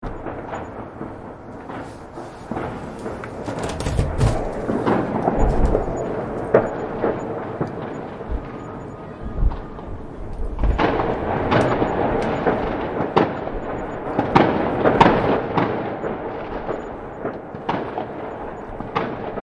城市中的烟花
描述：新的一年烟花在城市的声音